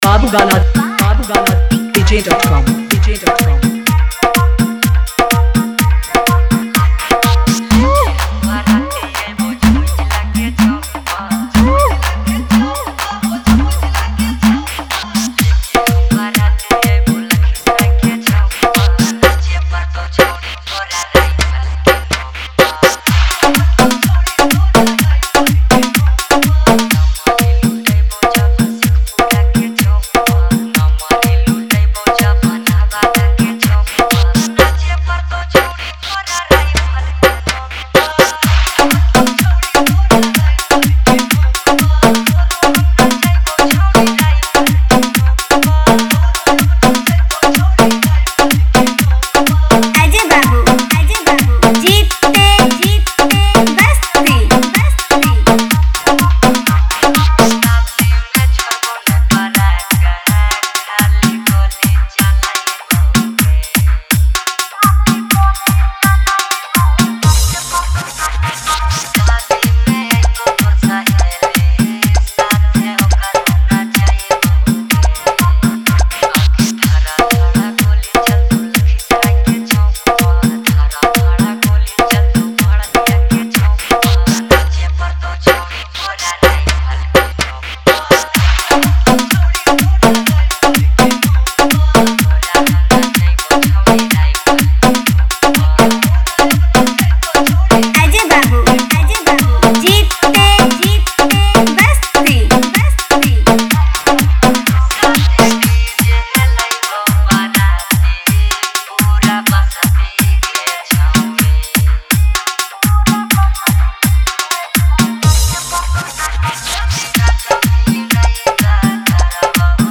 Category : Bhojpuri Wala Dj Remix